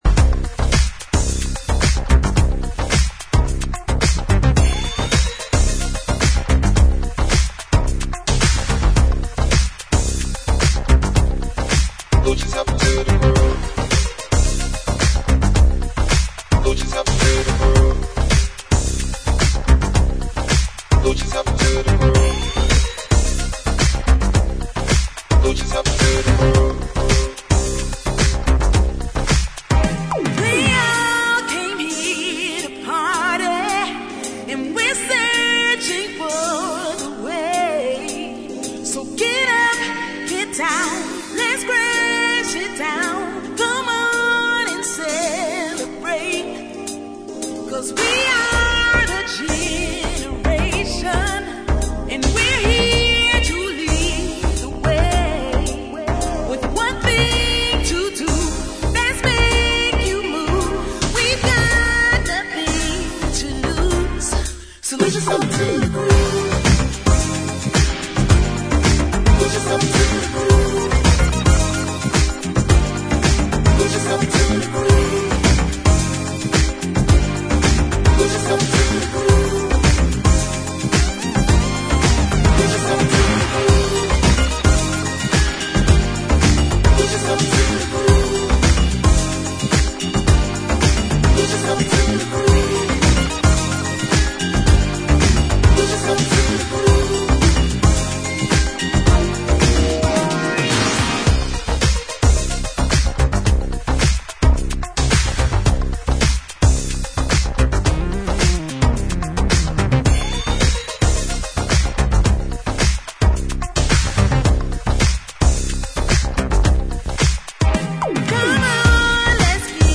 ジャンル(スタイル) HOUSE / DISCO / RE-EDIT